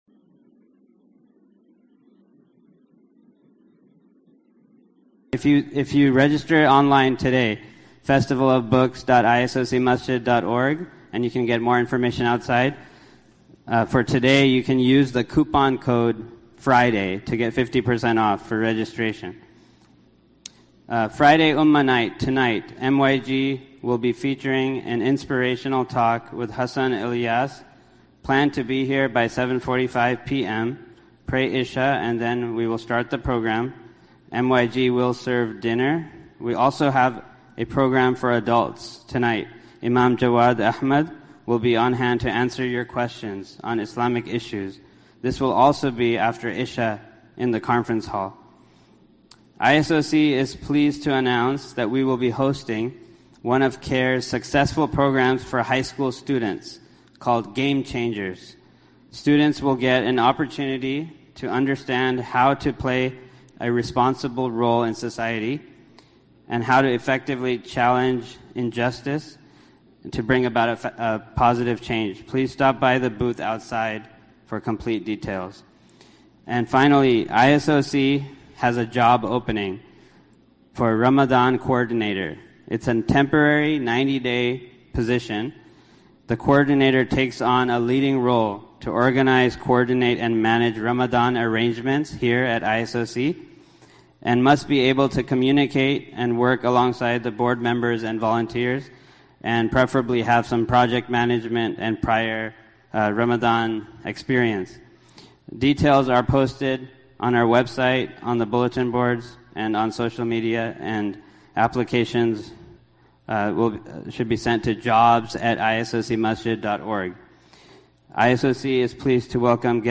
Khutbah 2/21/2020